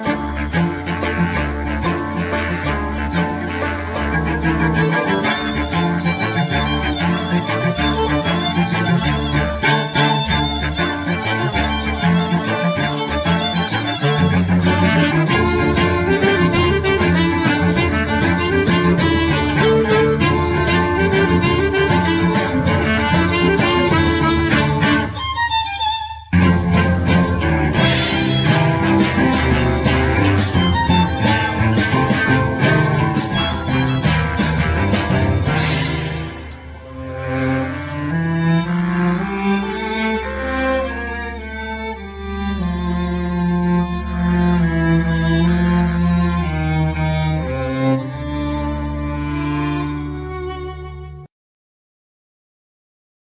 Clarinett
Violin
Elctric guitar
Cello
Percussions
Accordian